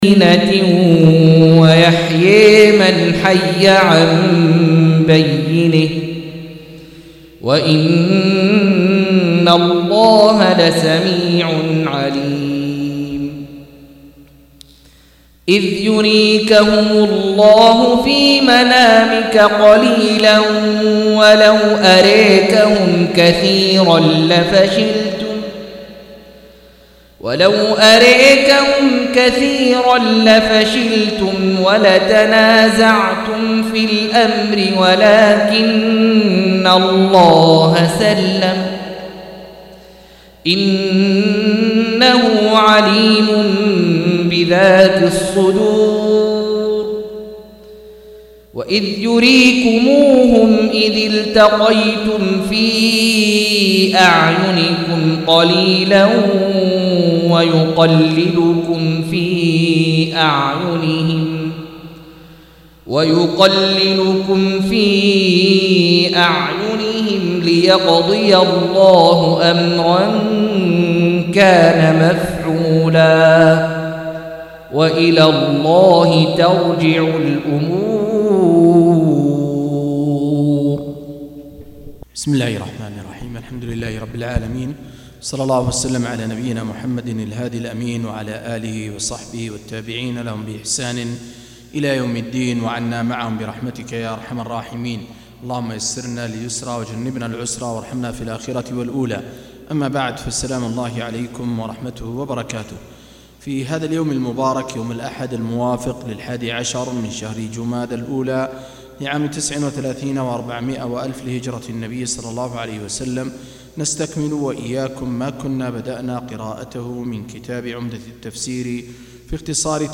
171- عمدة التفسير عن الحافظ ابن كثير رحمه الله للعلامة أحمد شاكر رحمه الله – قراءة وتعليق –